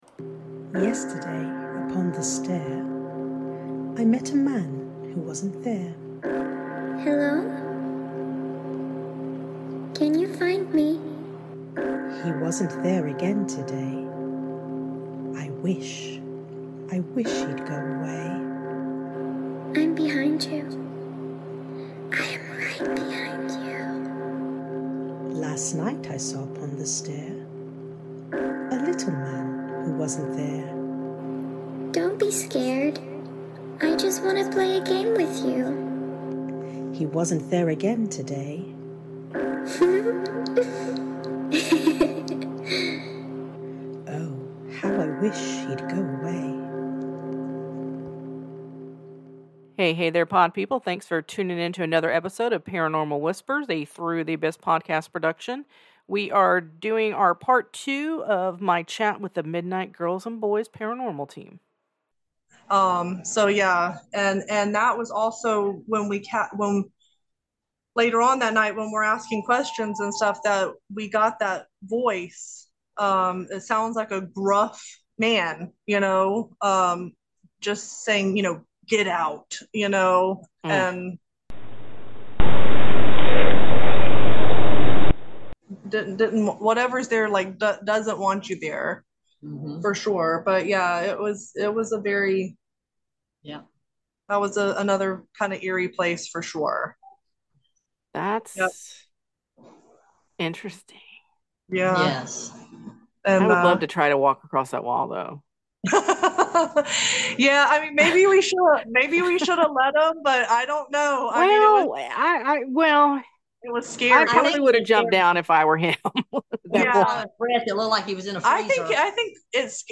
This is part 2 of our 2 part series with the Midnight Girls and Boys. They are fellow paranormal investigators who have had some amazing experiences. Be sure to listen closely as you'll hear some EVPs they shared with me!